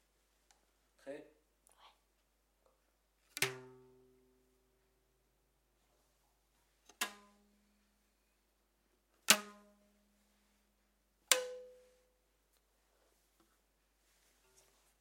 指弹紧绷的橡皮筋
描述：指弹紧绷的橡皮筋。像一个松垮的大提琴弦声。 用AT4021麦克风、Marantz PMD 661录音机。
标签： 大提琴 橡皮筋
声道单声道